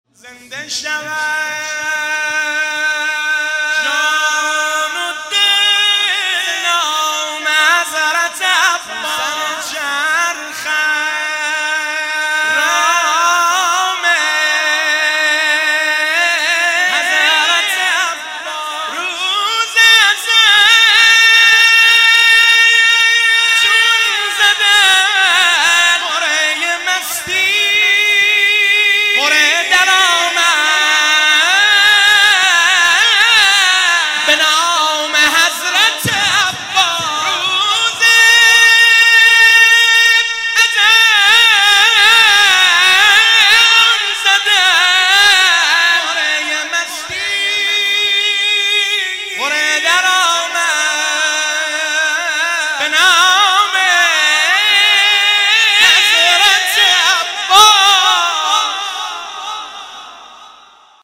زمزمه میلاد امام حسن مجتبی (ع) 1398
هیئت غریب مدینه امیرکلای بابل